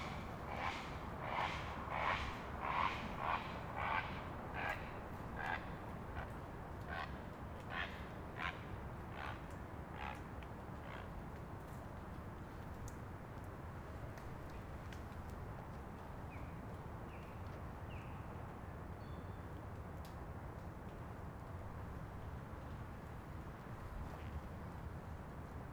VANCOUVER SOUNDSCAPE, JUNE, 1996
Deer Lake, heron 0:25
20. Heron overhead. Listen for wings flapping in the wind.